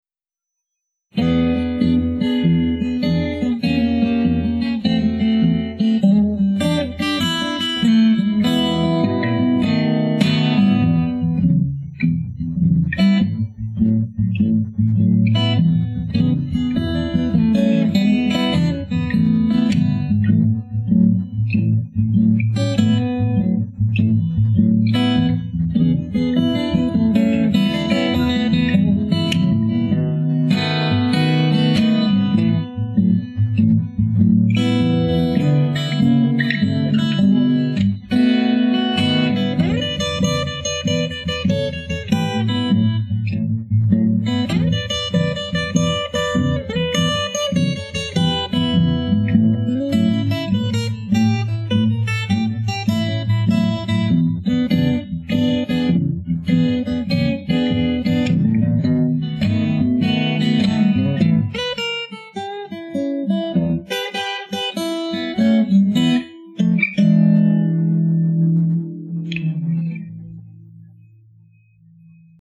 Virgin/Raw Acoustic Blues for mixing
Applied noise reduction to reduce hiss. Re-panned it. EQ'd to get the highs and lows more even. Some compression applied to get it more even. Some moderate reverb - just to make the room sound a little bigger.